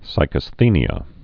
(sīkəs-thēnē-ə)